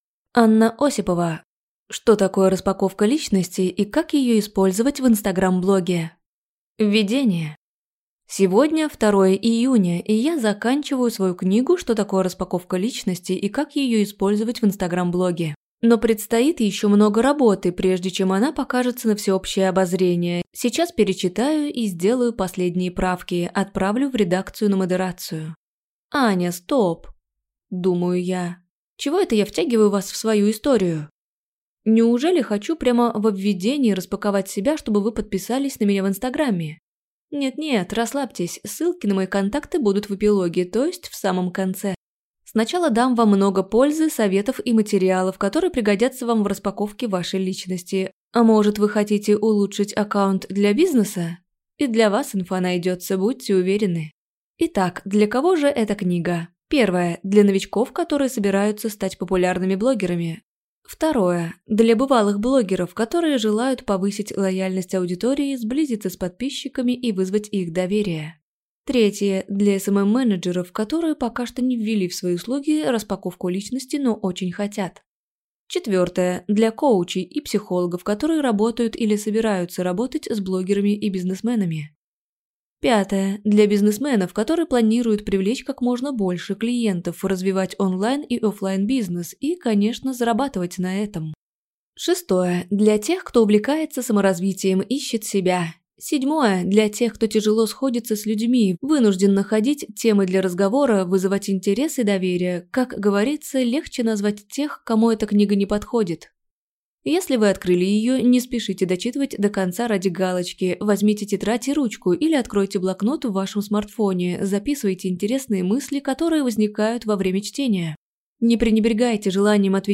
Аудиокнига Что такое распаковка личности и как её использовать в Инстаграм блоге | Библиотека аудиокниг